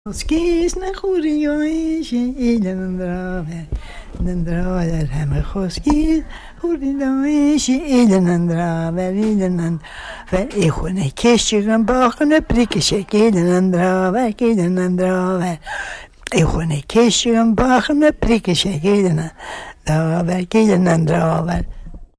Mouth Vocal